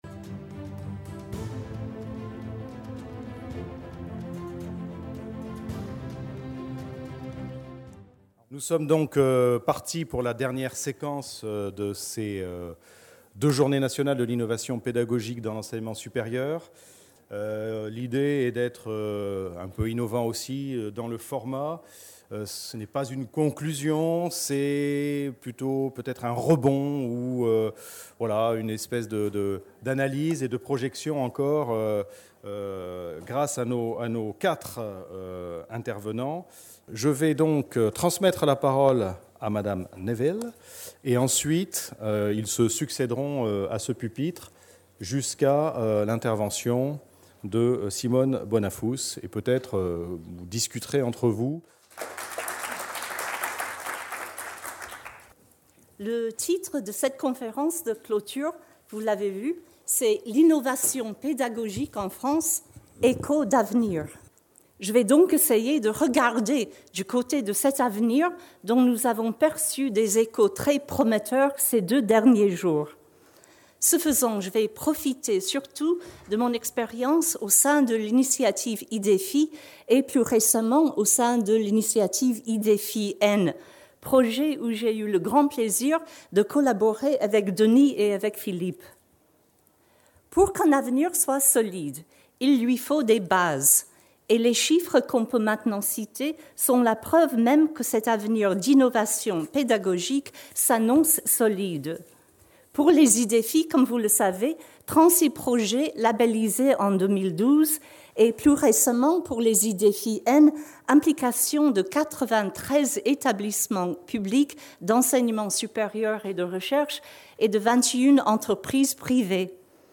JIPES 2016 // Conférence de clôture : L’innovation pédagogique en France, échos d’avenir | Canal U